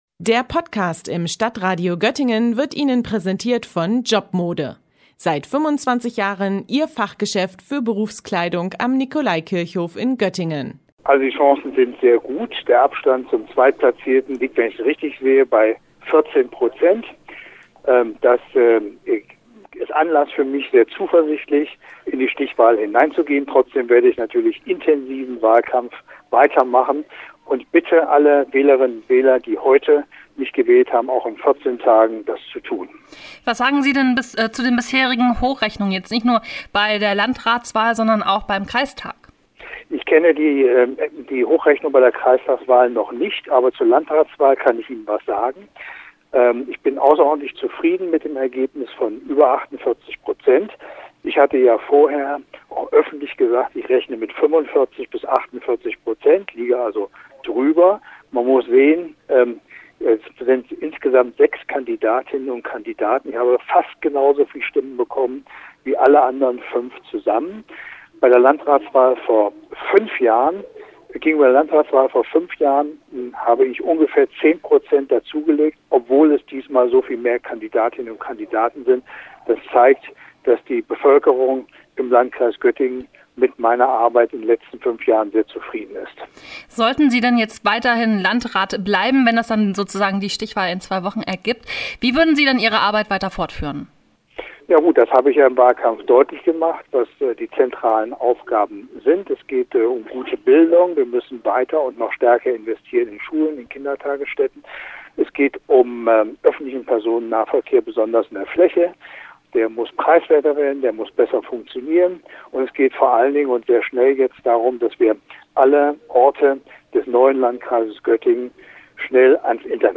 Beiträge > Interview mit Bernhard Reuter zu den Ergebnissen der Landratswahl - StadtRadio Göttingen